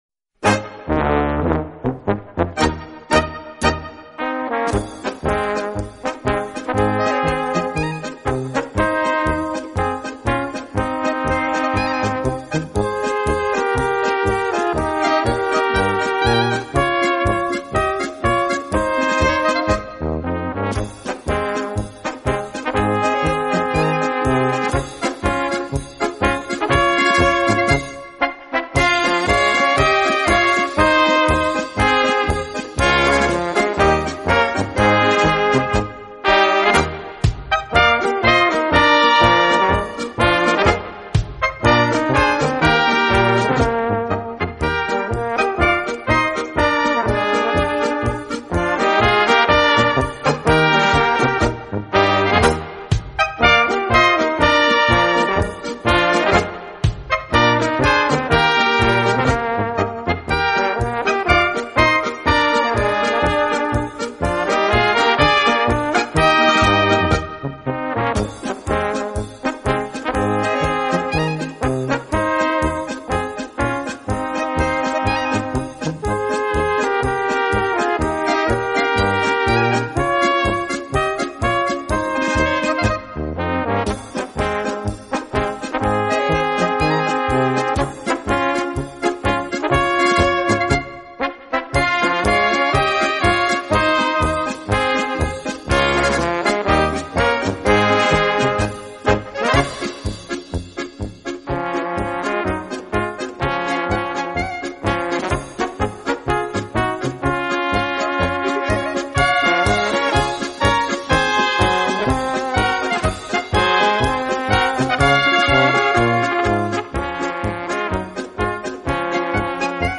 Gattung: für kleine Besetzung
Besetzung: Kleine Blasmusik-Besetzung